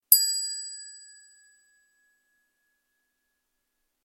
دانلود آهنگ دینگ از افکت صوتی اشیاء
دانلود صدای دینگ از ساعد نیوز با لینک مستقیم و کیفیت بالا
جلوه های صوتی